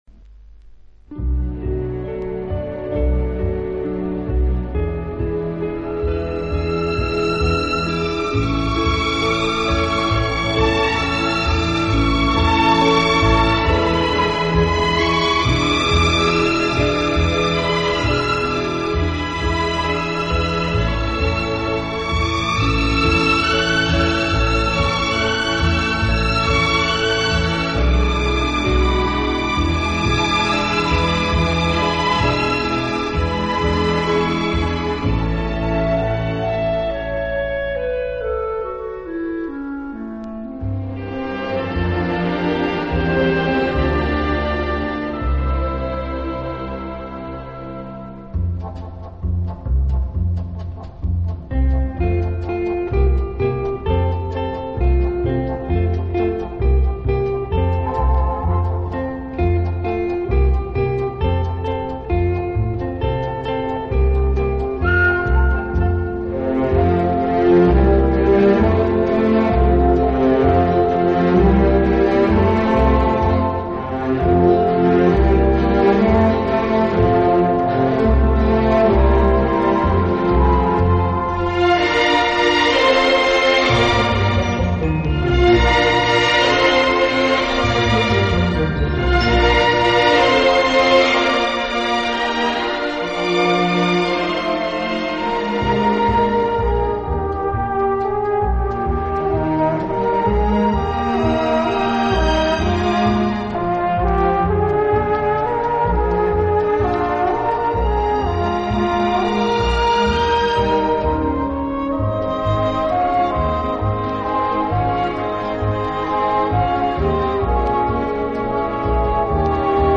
舒展，旋律优美、动听，音响华丽丰满。